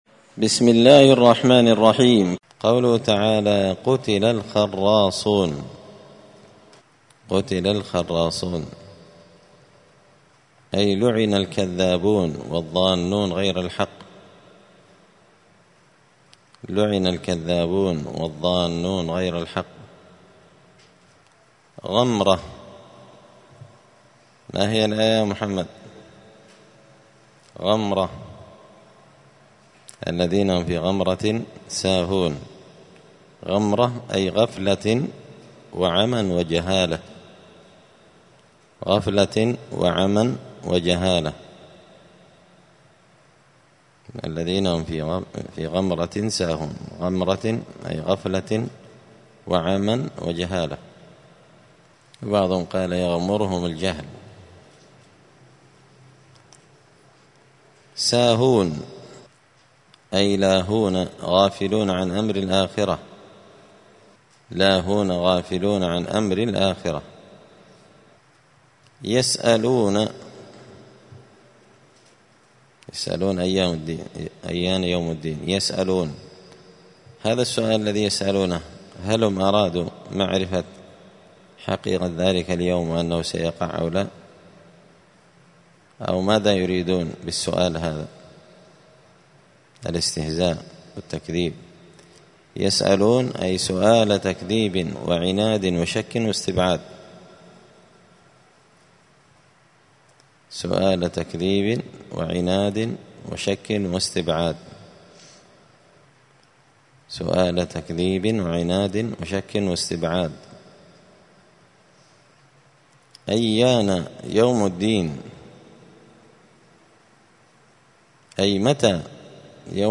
زبدة الأقوال في غريب كلام المتعال الدرس الثامن والخمسون بعد المائة (158)